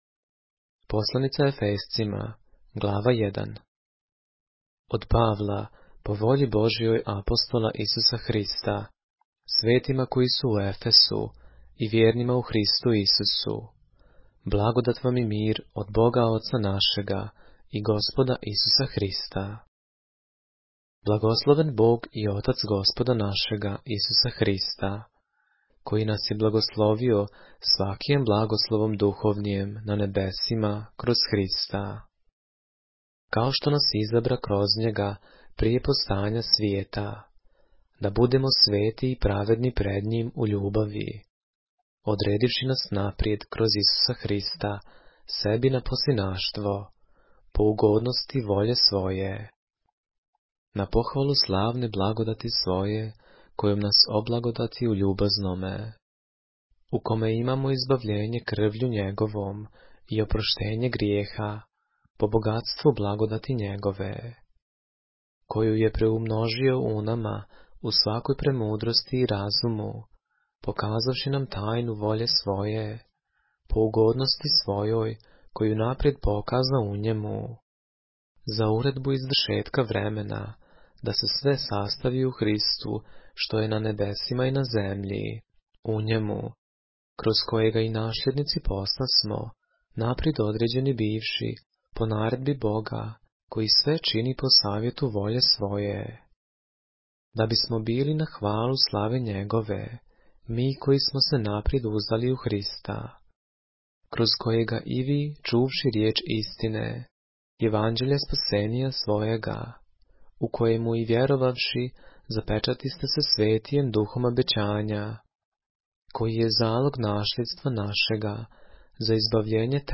поглавље српске Библије - са аудио нарације - Ephesians, chapter 1 of the Holy Bible in the Serbian language